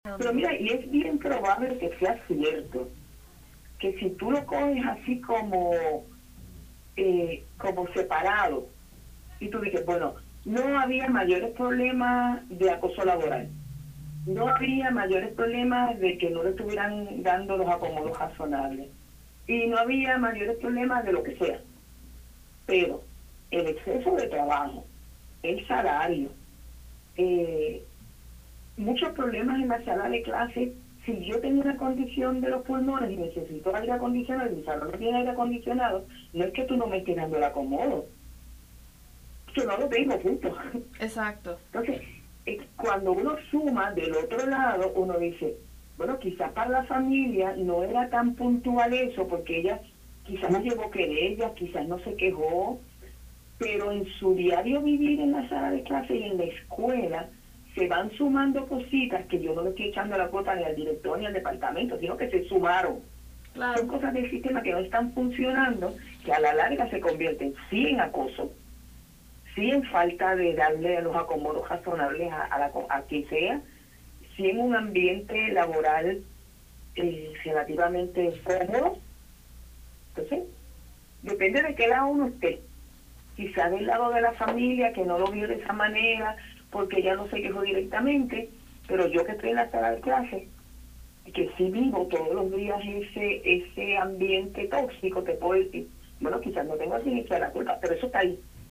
en entrevista con Radio Isla